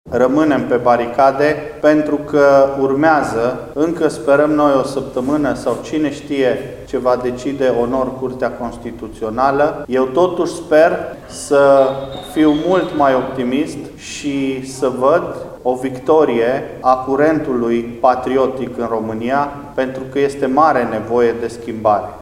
Proiecții pentru viitor se vor face după finalizarea procesului de renumărare a voturilor, spune deputatul de Timiș, Ciprian Titi Stoica.